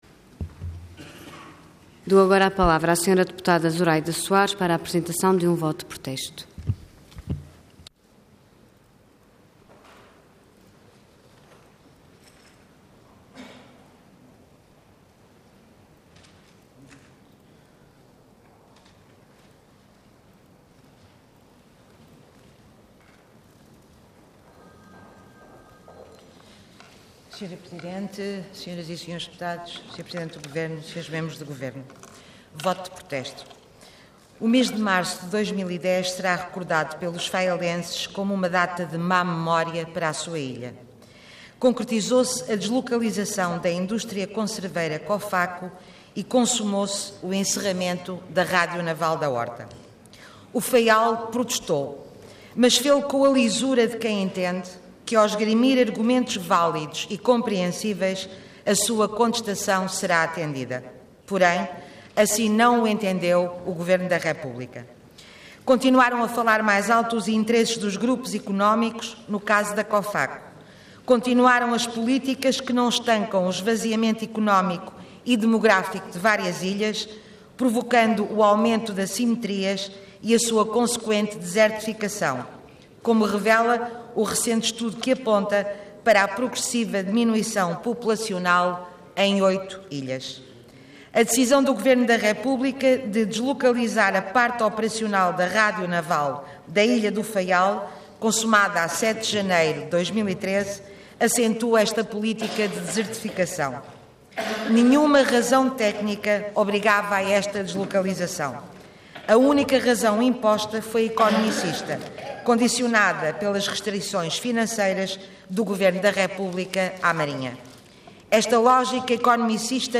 Intervenção Voto de Protesto Orador Zuraida Soares Cargo Deputada Entidade BE